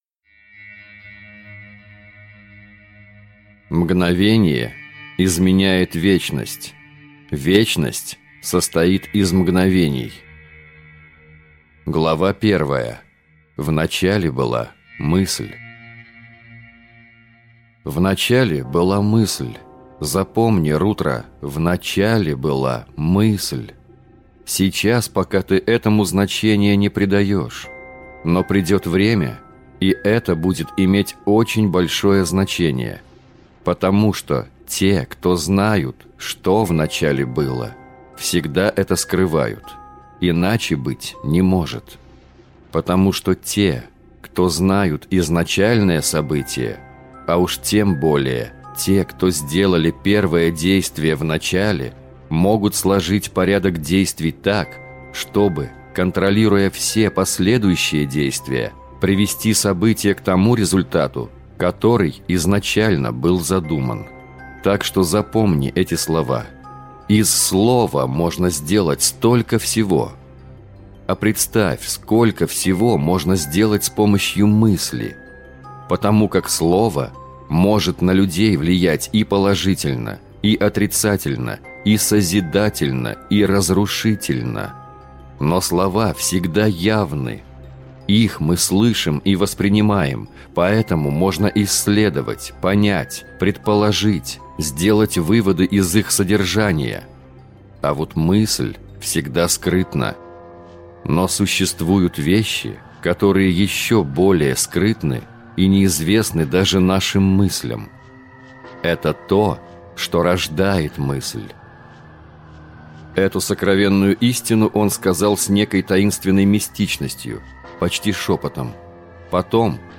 Аудиокнига Параллельные миры – one. Огонь и пламя | Библиотека аудиокниг